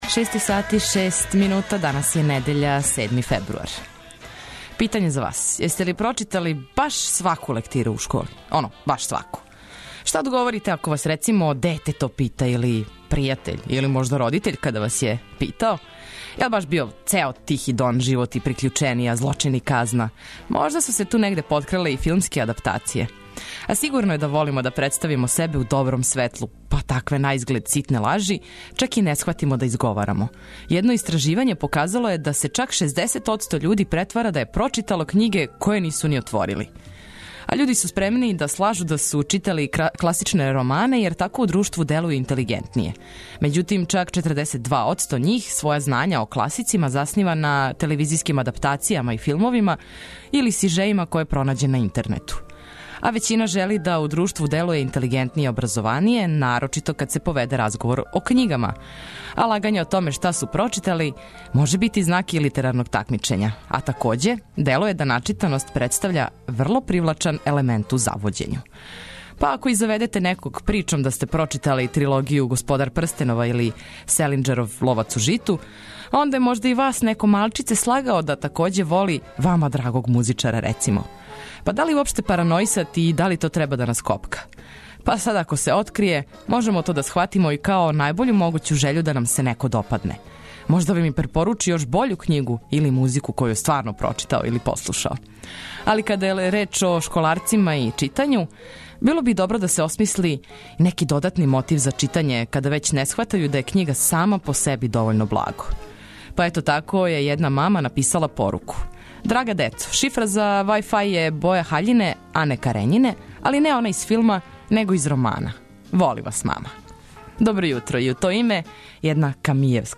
Недељно јутро започињемо лежерно, лагано, ведро и опуштено – на вама је да се препустите доброј музици, а ми ћемо вас уз обиље корисних, битних и позитивних информација поставити на „десну ногу“!